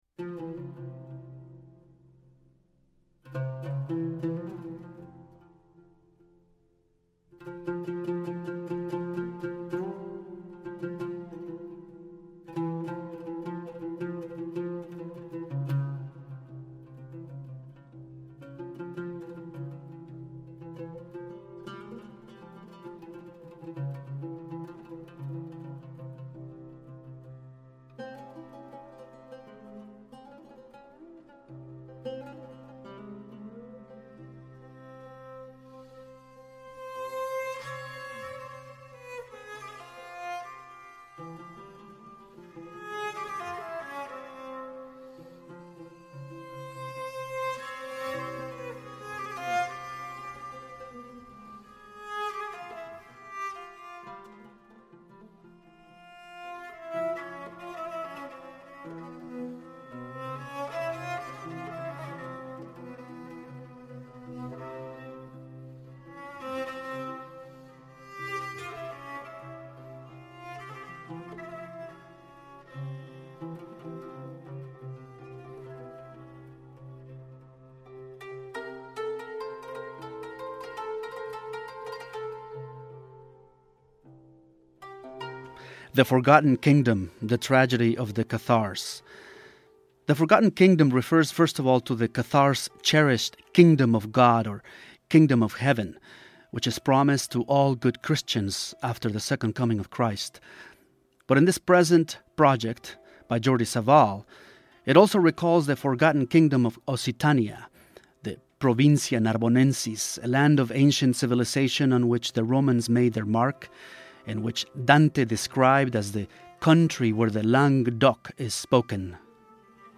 Interview with Jordi Savall